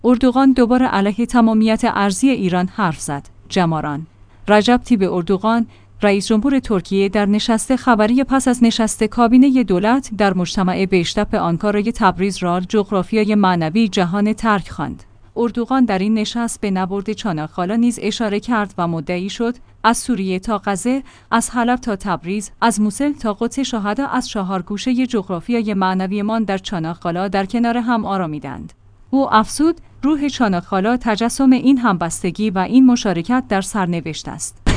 جماران/ رجب طیب اردوغان، رئیس جمهور ترکیه در نشست خبری پس از نشست کابینه دولت در مجتمع بئش‌تپه آنکارا تبریز را «جغرافیای معنوی» جهان ترک خواند.